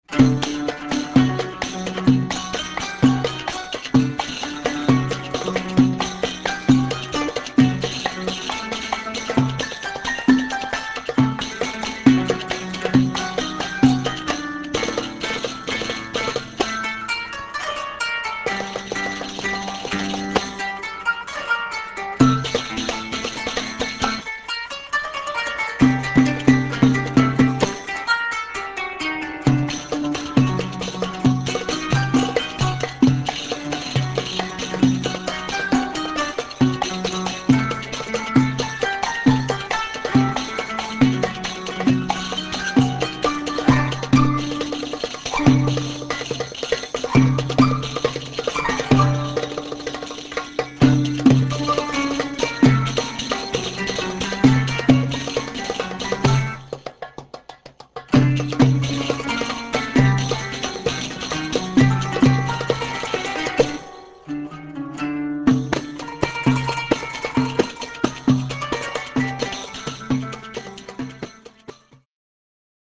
qanun (zither)
doumbec and tambourine